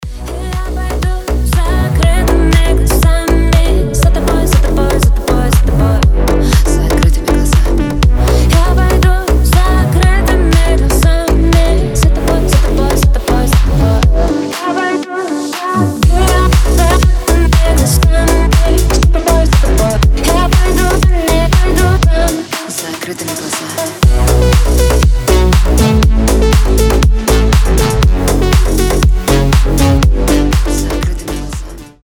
• Качество: 320, Stereo
женский голос
Club House
чувственные
Легкий клубный мотив